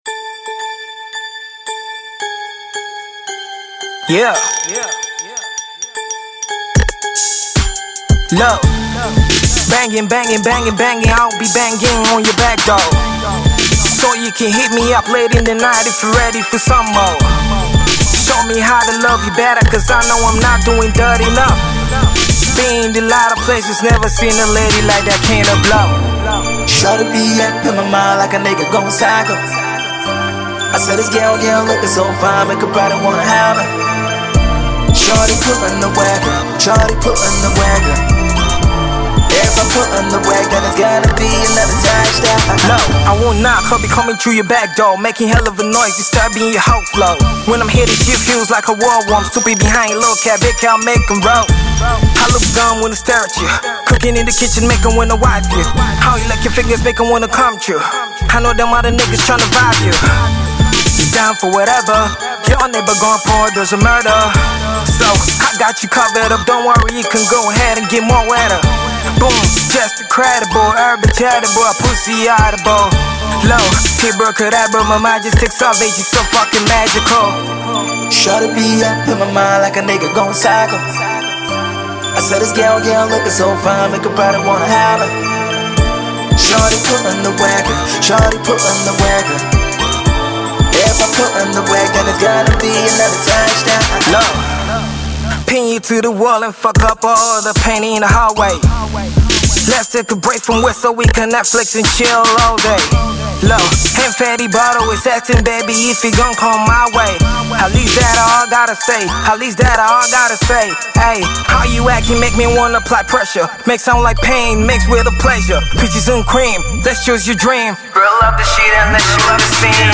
This is a dope HipHop tune from Ghana.